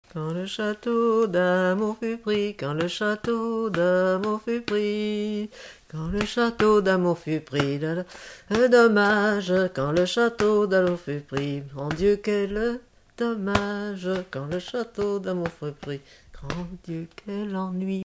danse ronde
Genre laisse
Pièce musicale inédite